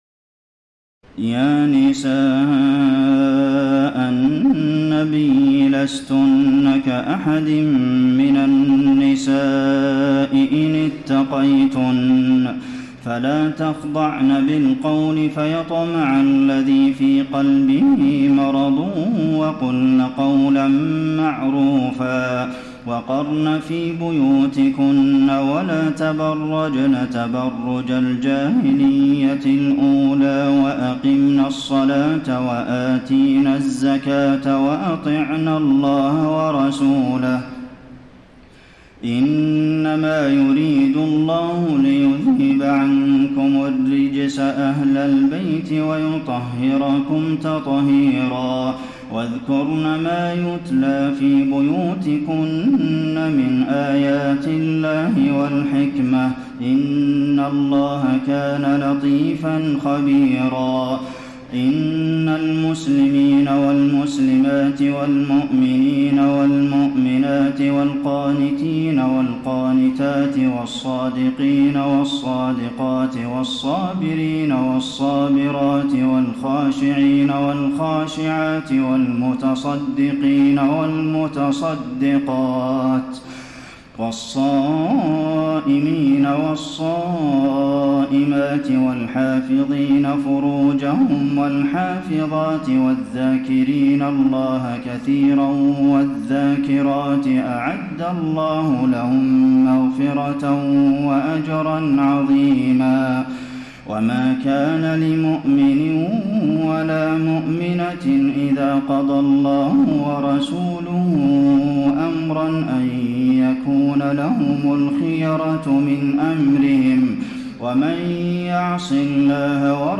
تراويح ليلة 21 رمضان 1433هـ من سور الأحزاب (32-73) وسبأ (1-23) Taraweeh 21 st night Ramadan 1433H from Surah Al-Ahzaab and Saba > تراويح الحرم النبوي عام 1433 🕌 > التراويح - تلاوات الحرمين